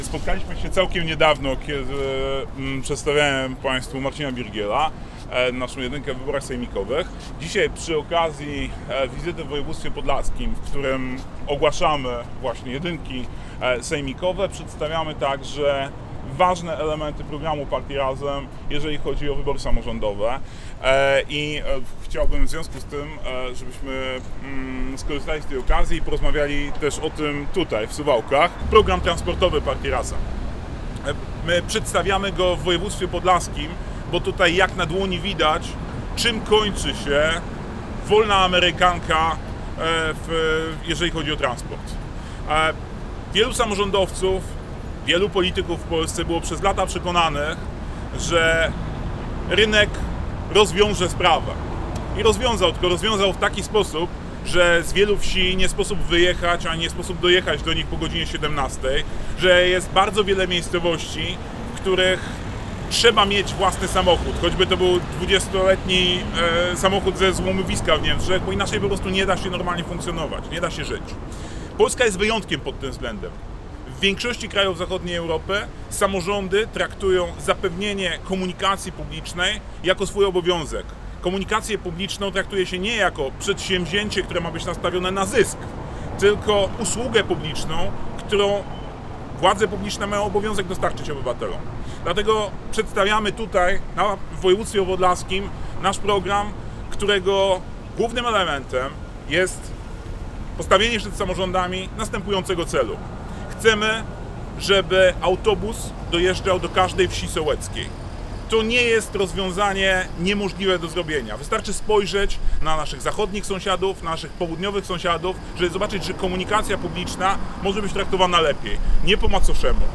Brefing prasowy przed Urzędem Miejskim w Suwałkach był okazją do poruszenia kilku kwestii, znajdujących się w kręgu zainteresowania członków Razem.
Mówi Adrian Zandberg:
Adrian_Zandberg_RAZEM.wav